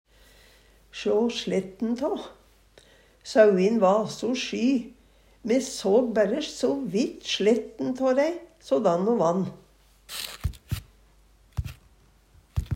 DIALEKTORD PÅ NORMERT NORSK sjå sletten tå få eit kort glimt av, skimte Eksempel på bruk Sauin va so sjy.